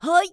YX蓄力5.wav 0:00.00 0:00.33 YX蓄力5.wav WAV · 28 KB · 單聲道 (1ch) 下载文件 本站所有音效均采用 CC0 授权 ，可免费用于商业与个人项目，无需署名。
人声采集素材